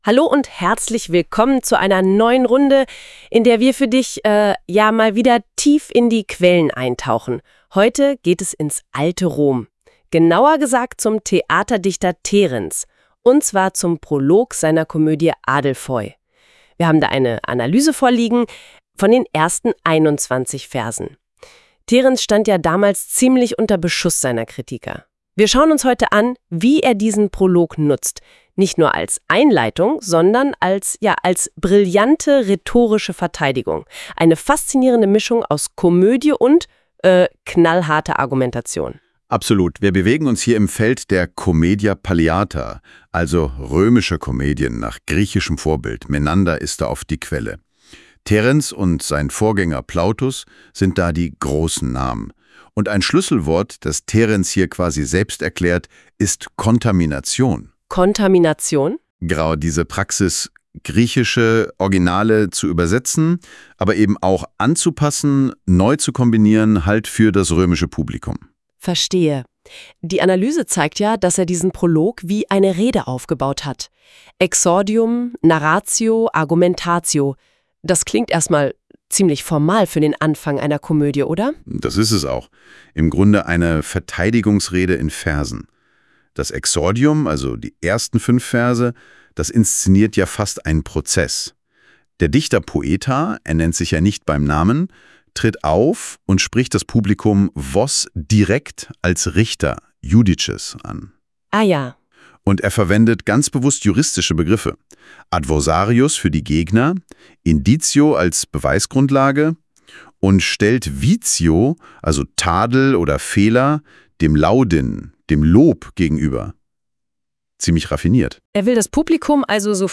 Die Auswahl der Originaltexte entstammt der Sammlung der alten Staatsexamenklausuren. Erstellt mit Google Notebook LM